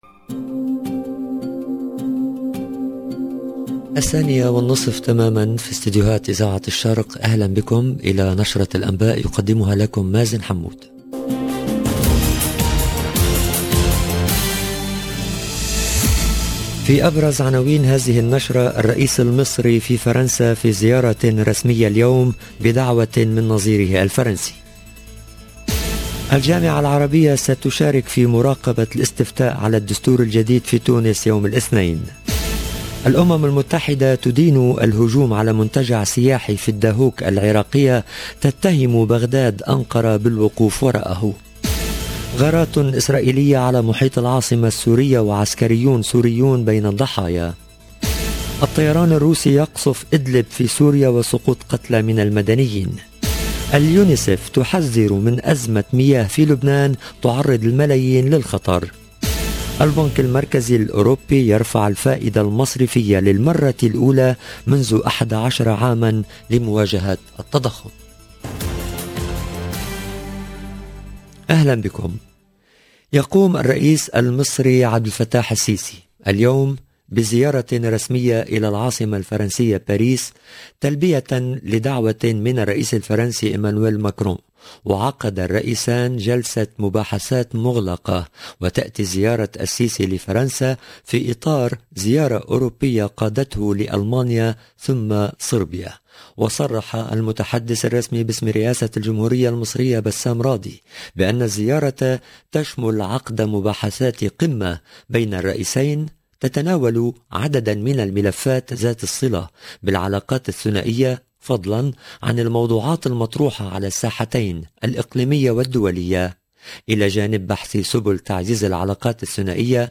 LE JOURNAL EN LANGUE ARABE DE LA MI-JOURNEE DU 22/07/22